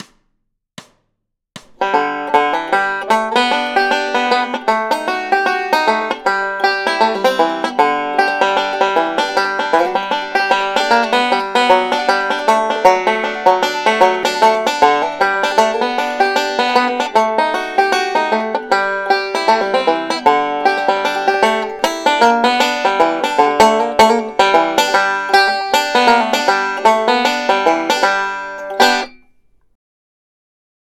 pretty standard solo